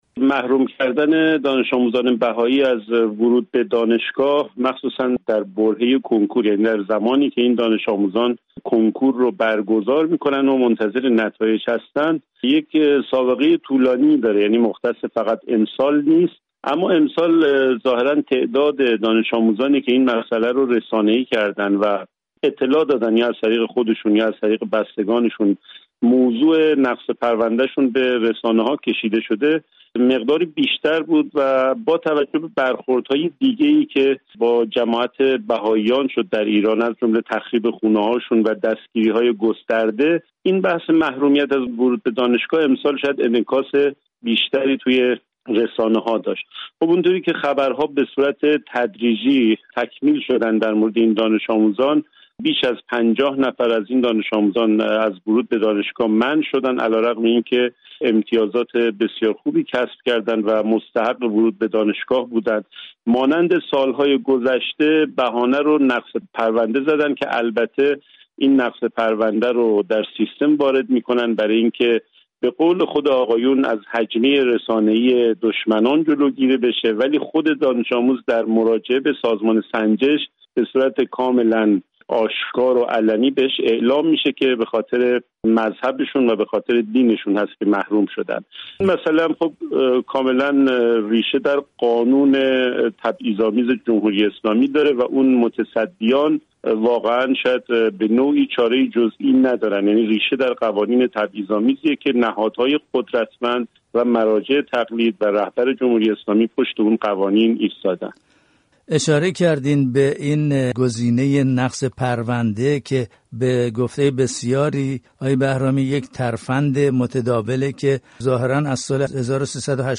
ارزیابی یک روزنامه‌نگار از افزایش شمار شهروندان بهایی محروم از تحصیل در ایران